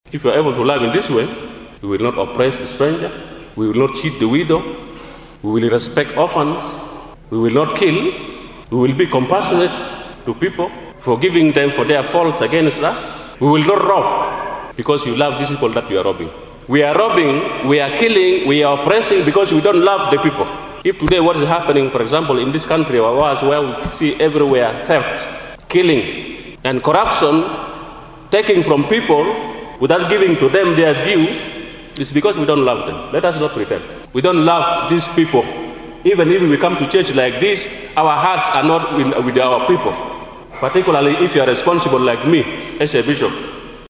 The Catholic Bishop of Yei Diocese,Bishop Alex Lodiong Sakor.
The Catholic prelate made the remarks on Sunday at Christ the king cathedral in Yei during first mass.